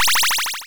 powerup_sfx.wav